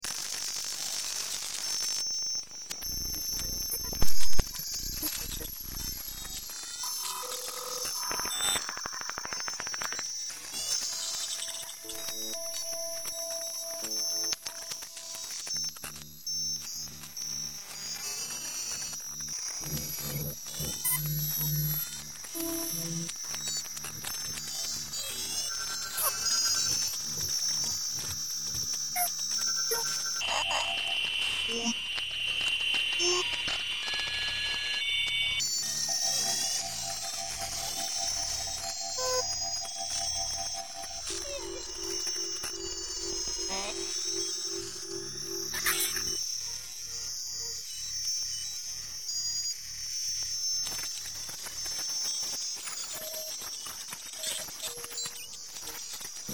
The sound coming out of the speakers is driven by five CD players randomly emitting noise samples. These sounds are recordings of technological glitches, warped tapes, skipping samples and vinyl poppings. Some of these are found sound, others created.